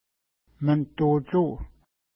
Pronunciation: məntu:-utʃu:
Pronunciation